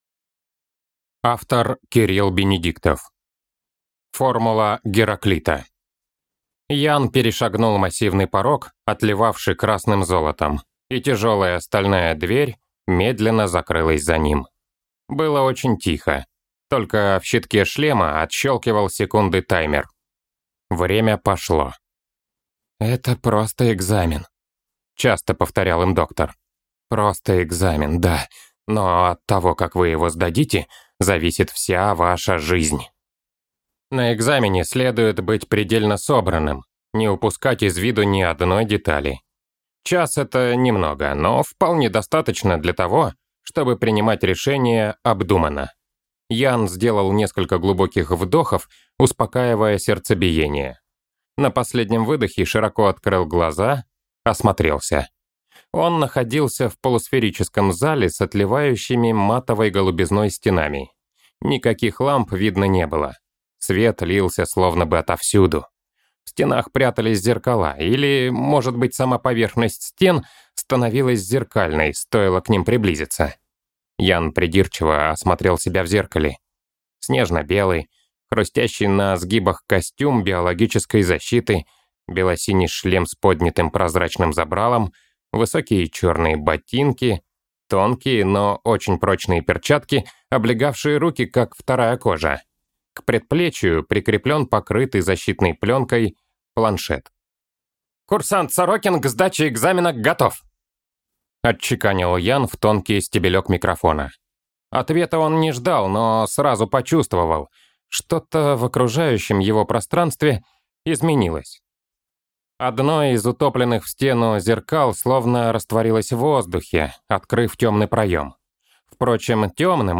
Аудиокнига Формула Гераклита | Библиотека аудиокниг
Прослушать и бесплатно скачать фрагмент аудиокниги